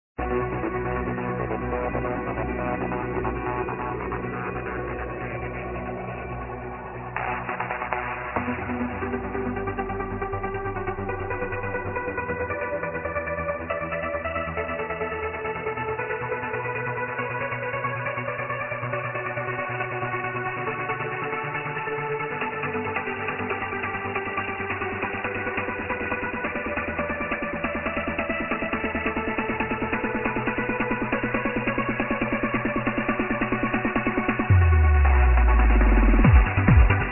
Unknown uplifting trance piece
wow amazing melody in this track